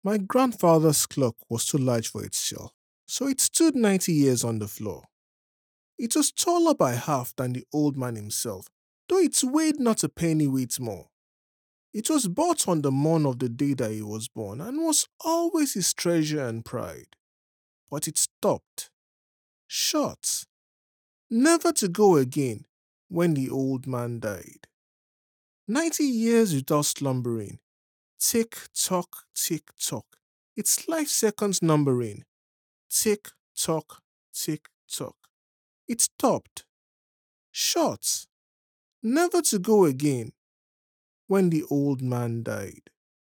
Male
Adult (30-50)
Studio Quality Sample
This File Is An Ivr Demo